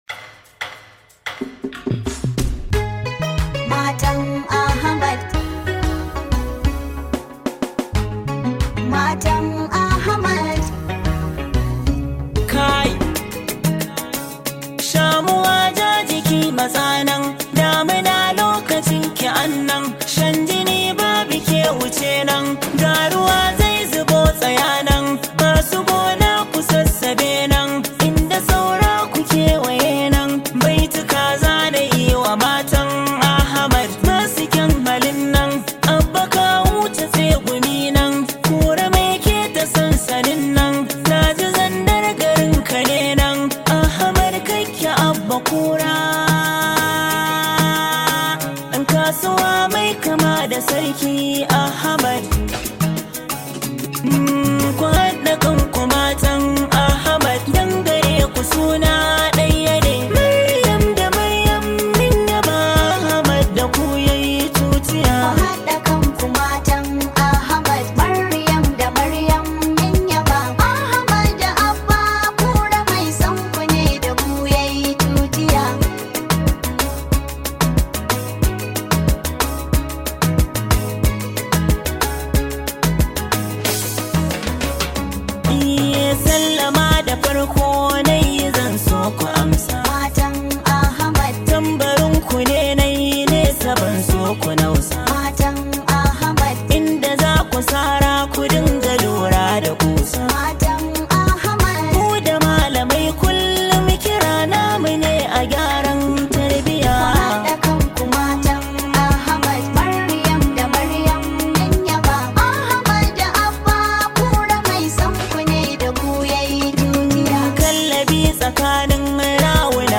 Hausa wedding song